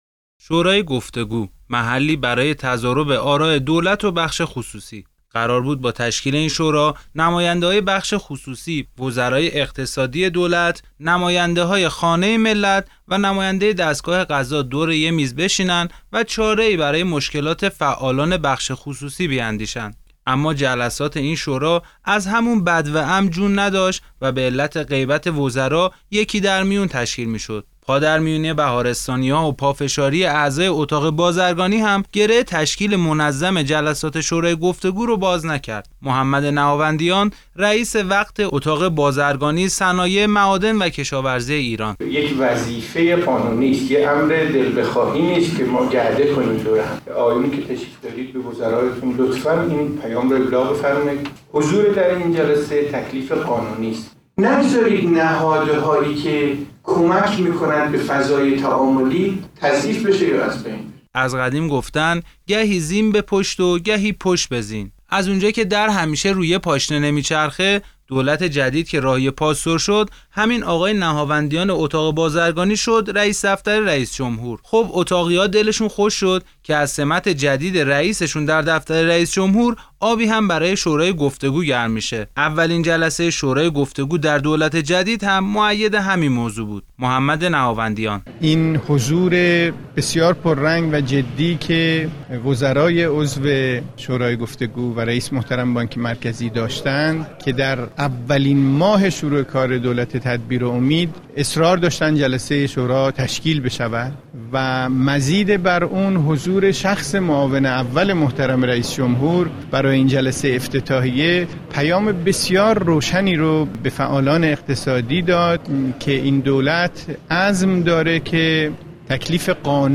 گزارش شنیدنی؛ شورای گفت‌وگو در اغما - تسنیم